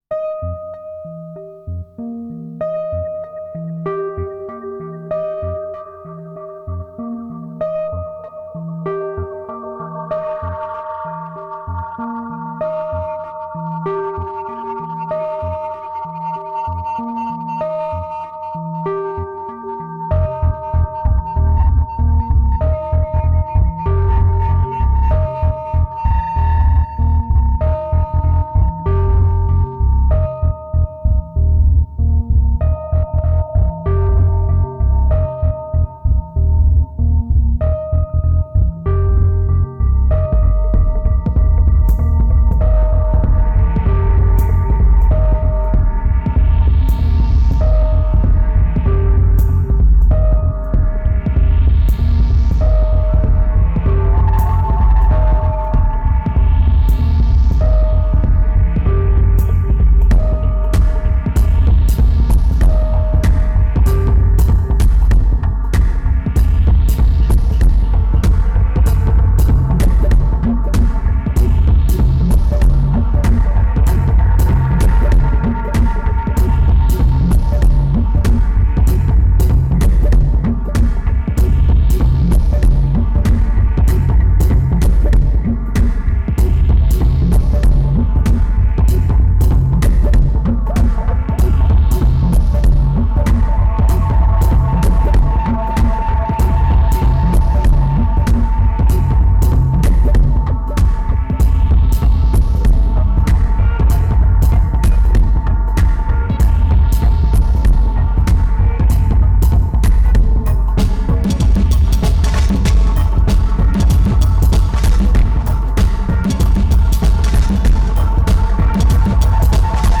2191📈 - 58%🤔 - 96BPM🔊 - 2010-06-23📅 - 63🌟